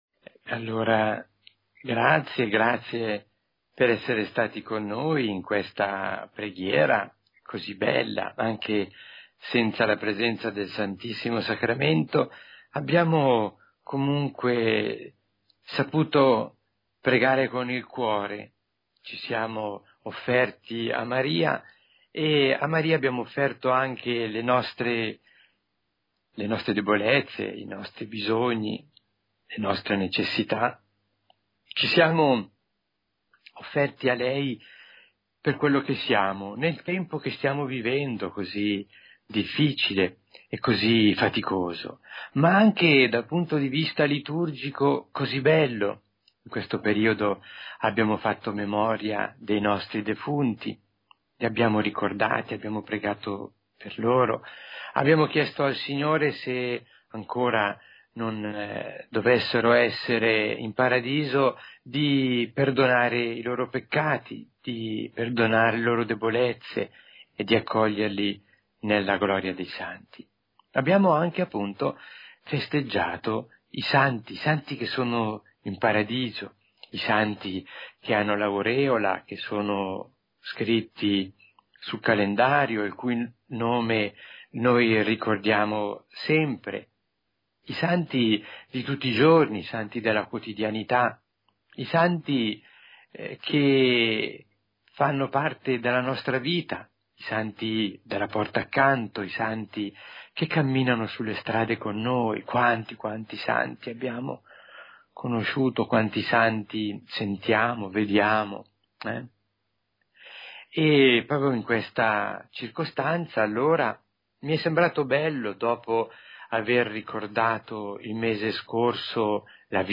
Meditazione